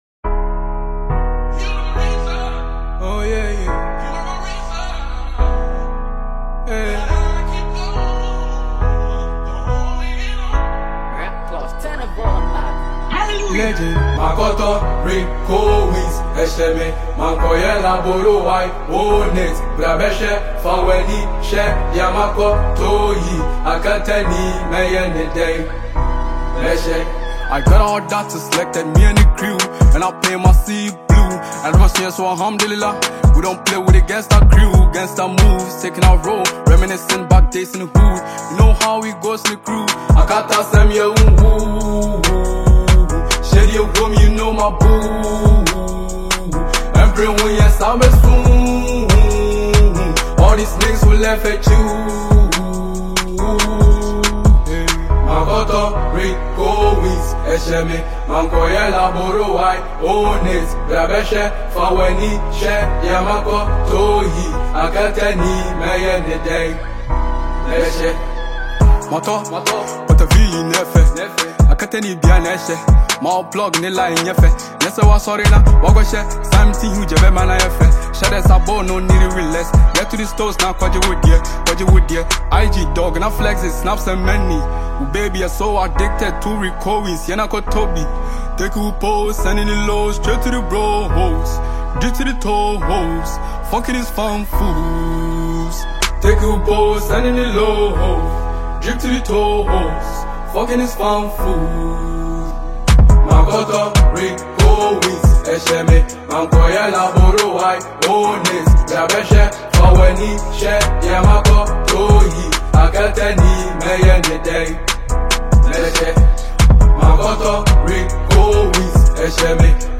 a fusion of trap energy and luxury lifestyle expression